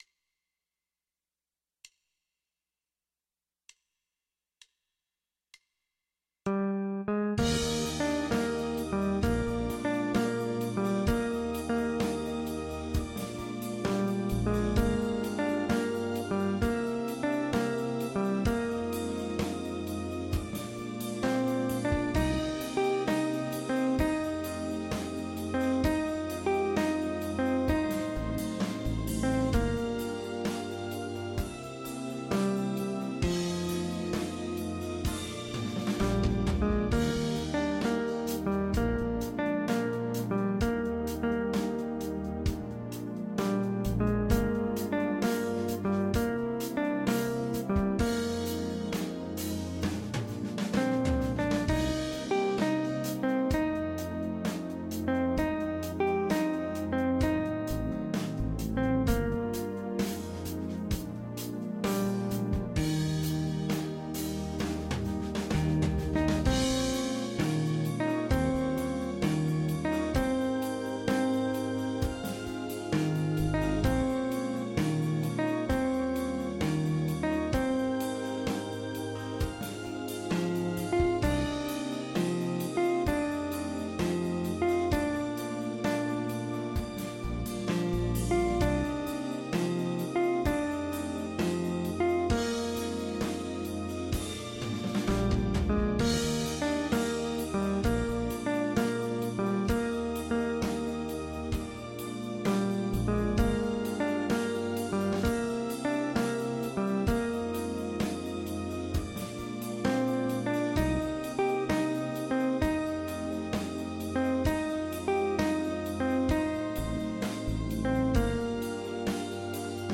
Select a style sheet with real drums